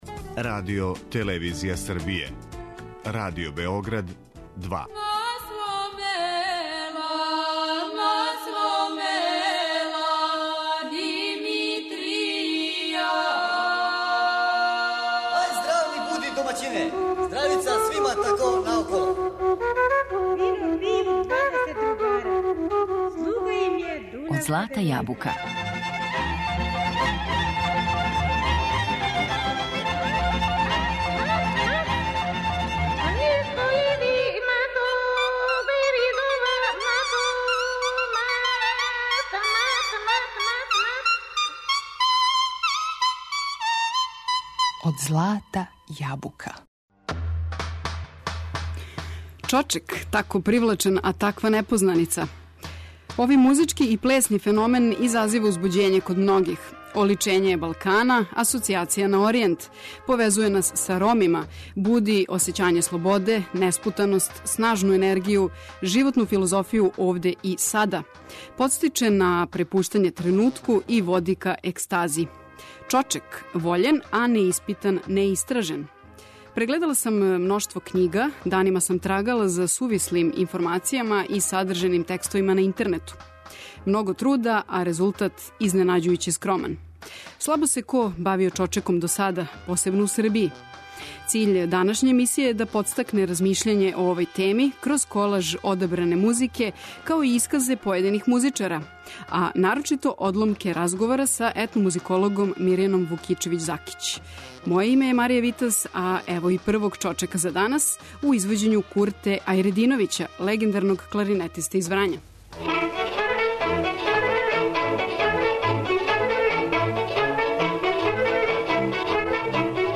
Посветићемо пажњу овом занимљивом и неухватљивом феномену, кроз одабране исказе етномузиколога и музичара, као и неке од снимака најлепших примера чочека.